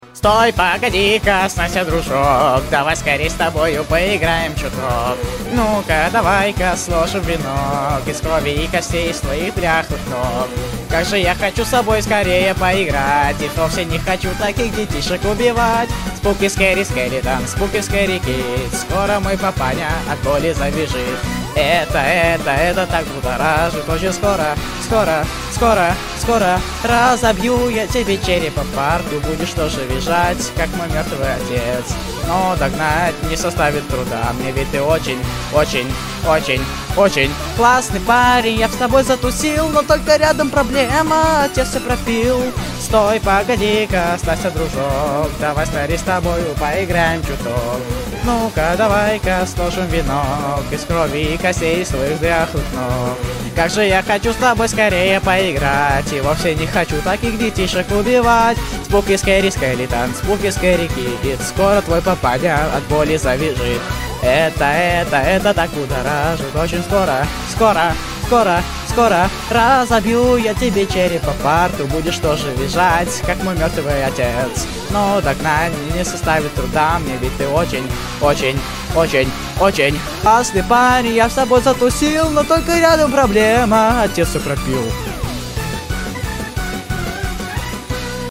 со словами (на русском)